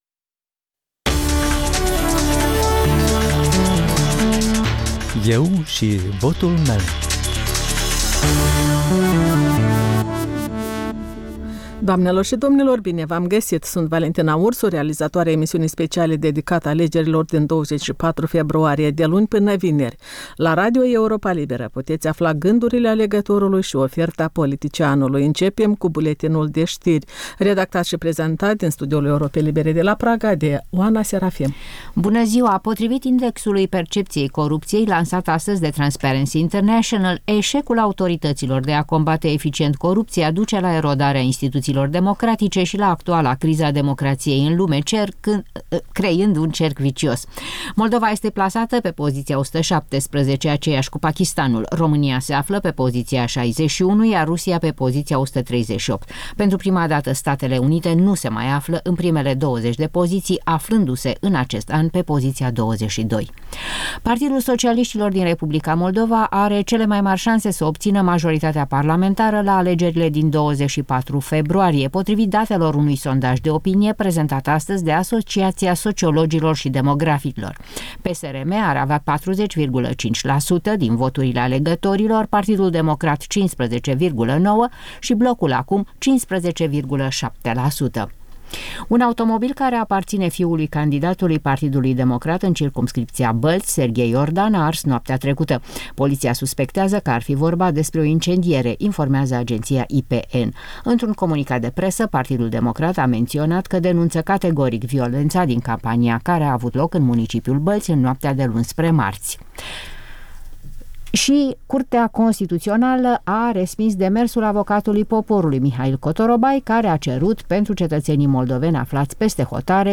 Analize, interviuri cu candidați și cu potențiali alegători, sondaje de opinie, reportaje din provincie. De luni până vineri, la ora 14.00, la Radio Europa Liberă.